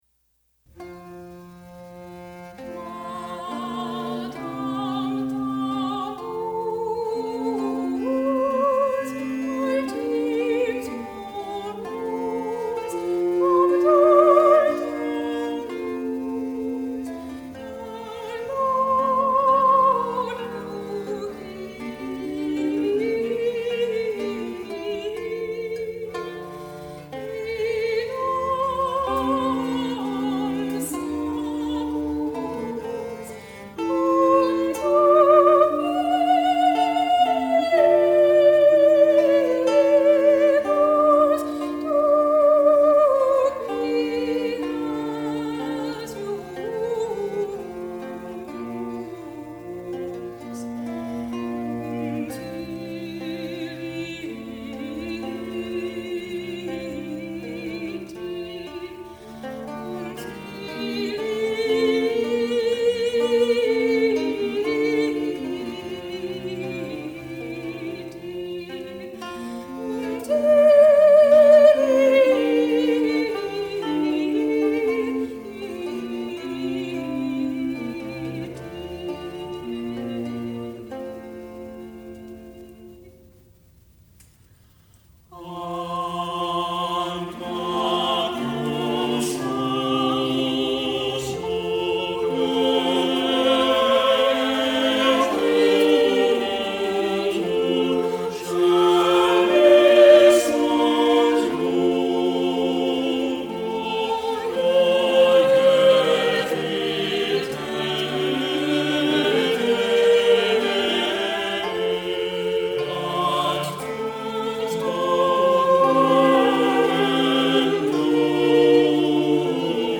The three voices supporting  the soprano melody appear in their contrapuntal complexity more suitable for instruments.  But since two of them have text underlay, a four-part vocal interpretation on the second stanza seemed appropriate.
soprano | Vocal and Instrumental Ensemble